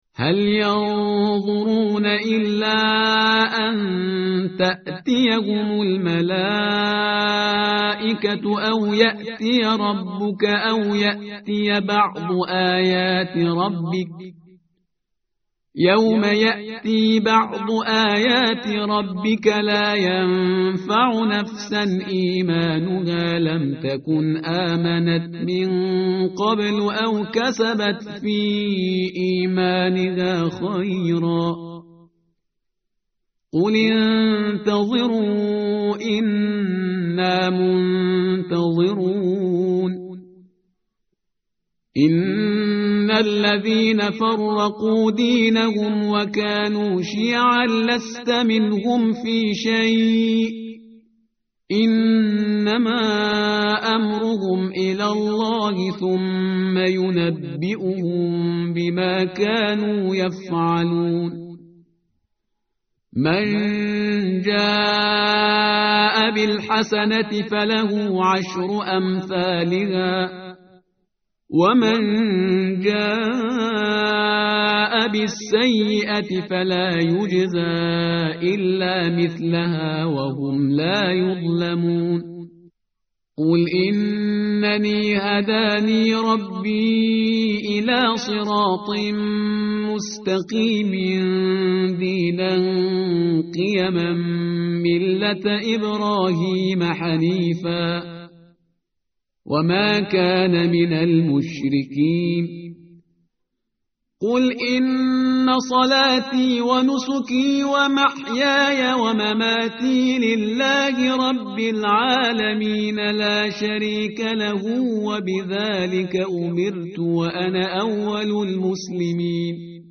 متن قرآن همراه باتلاوت قرآن و ترجمه
tartil_parhizgar_page_150.mp3